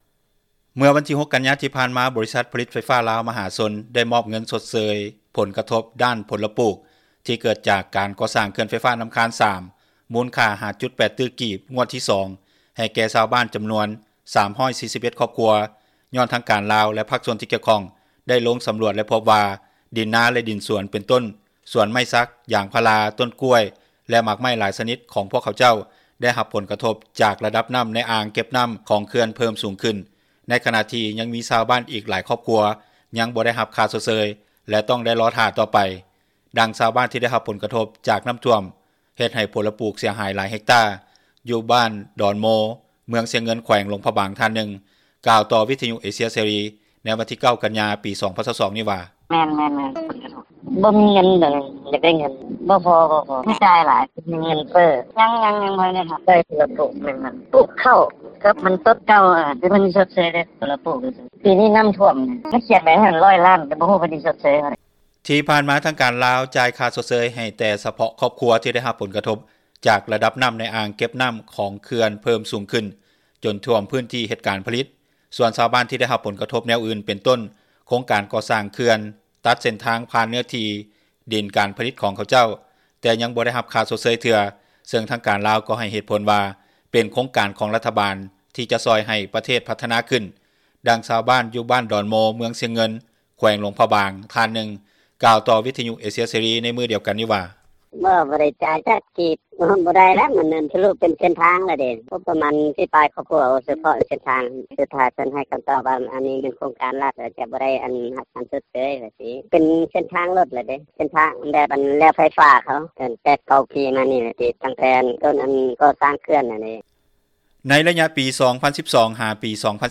ດັ່ງຊາວບ້ານ ຢູ່ບ້ານດອນໂມ ເມືອງຊຽງເງິນ ແຂວງຫຼວງພຣະບາງ ທ່ານນຶ່ງກ່າວຕໍ່ ວິທຍຸເອເຊັຽເສຣີ ໃນມື້ດຽວກັນນີ້ວ່າ:
ດັ່ງຊາວບ້ານຢູ່ບ້ານ ສາມັກຄີໄຊ ເມືອງຊຽງເງິນ ທ່ານນຶ່ງກ່າວວ່າ: